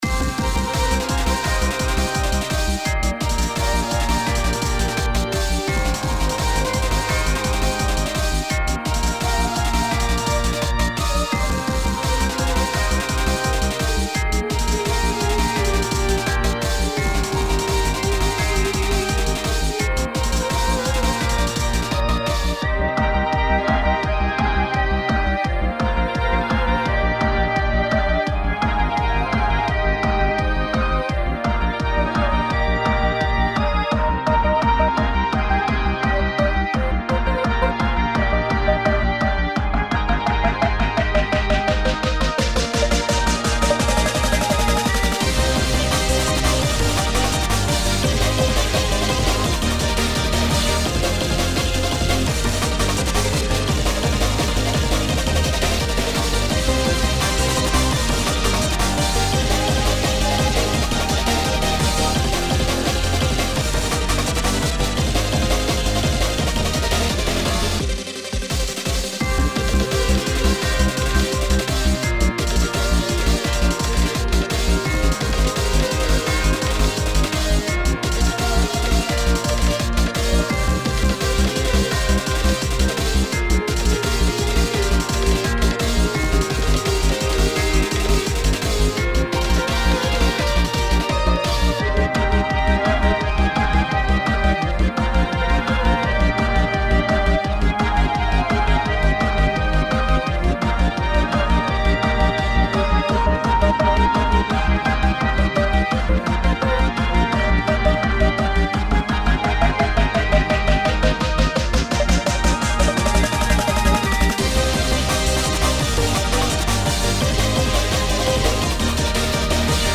壮大で美しいサウンドを目指しつつ、
盛り上がりの部分では、かっこよく疾走感のある曲にしました。